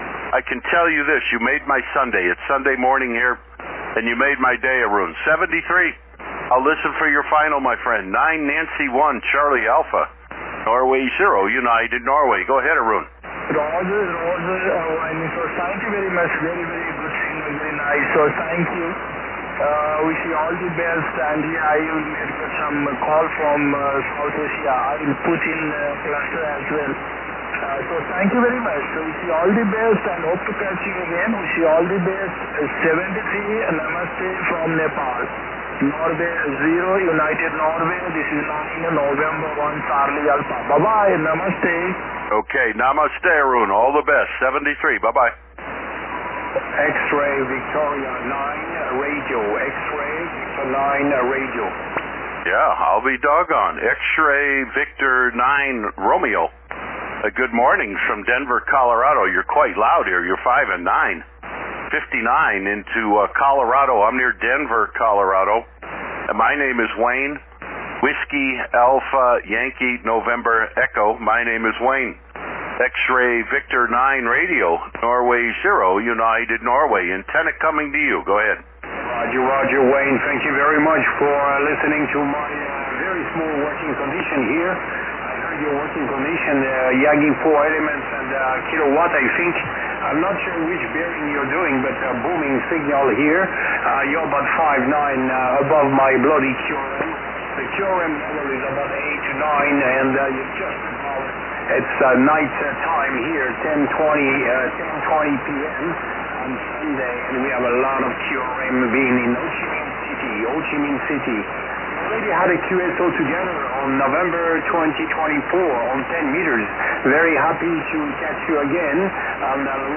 I read news 3Y0K Bouvet may (or may not) be on the air so I figured what the heck, I’ll point my antenna over Asia this morning and call CQ DX on 20M, 14.195. Maybe draw Bouvet out on the Long Path if they’re indeed playing radio?